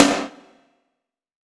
SNARE 102.wav